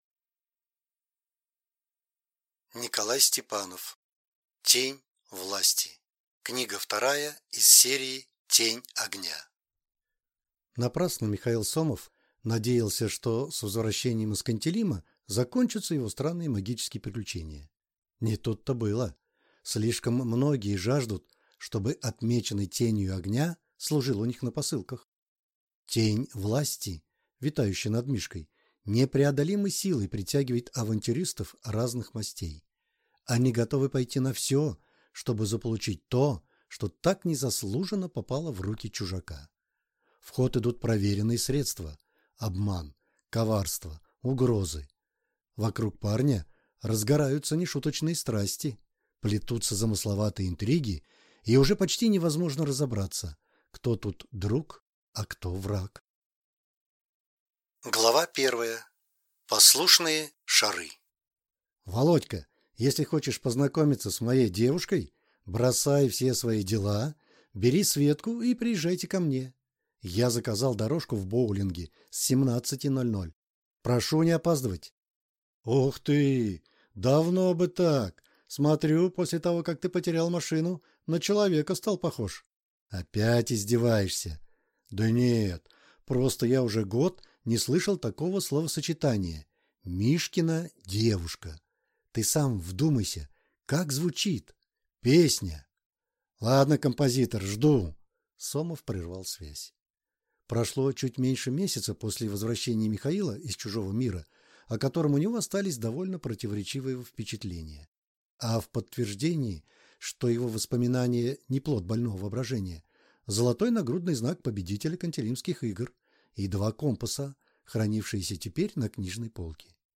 Aудиокнига Тень власти